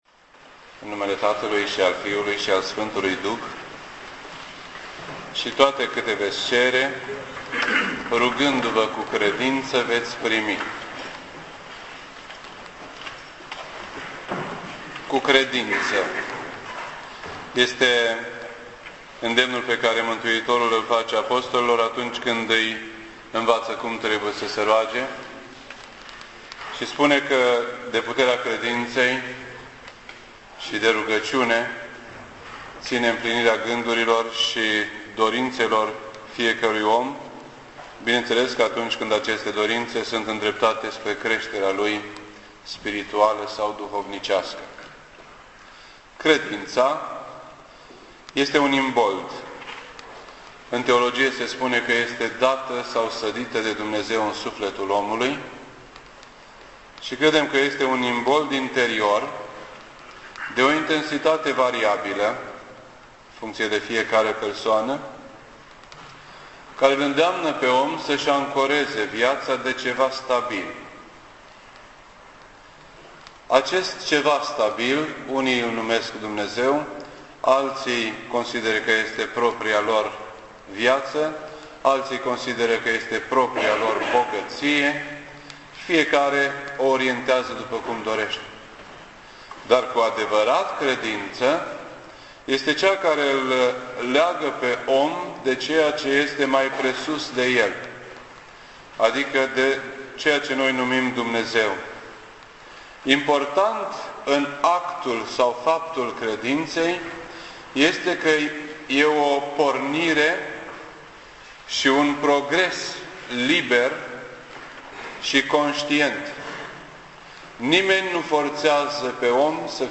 This entry was posted on Sunday, January 24th, 2010 at 8:42 PM and is filed under Predici ortodoxe in format audio.